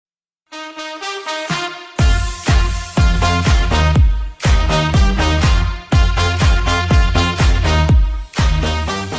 1-twitch-hitbox-alert-sounds-donation-follower-sub-sounds-audiotrimmer.mp3